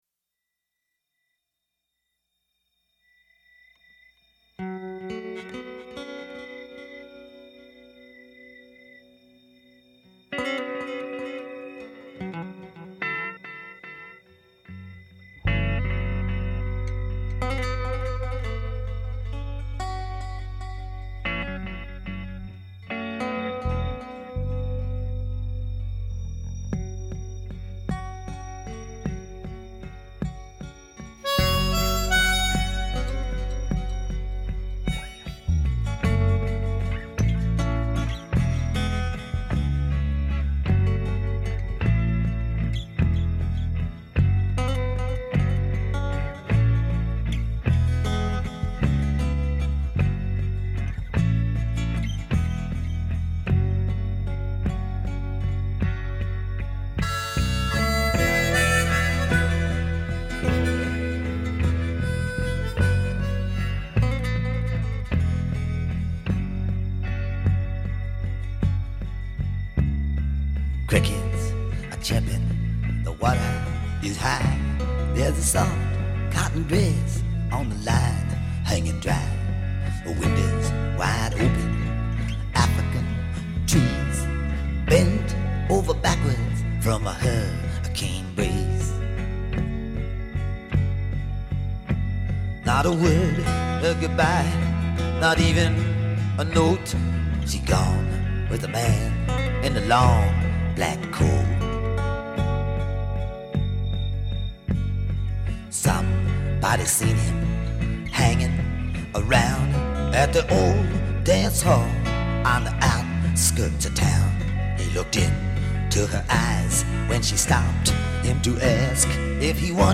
harmonica